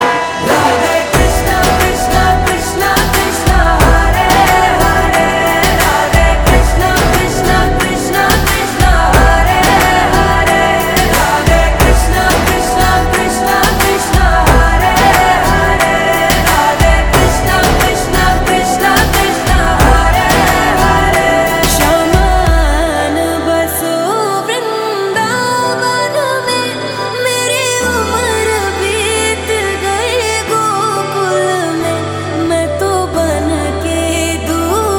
Category Devotional